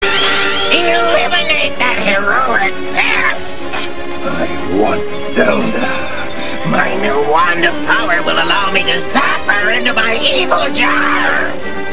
Character Quotes - Various
These MP3 quotes are captured direct from the DVD.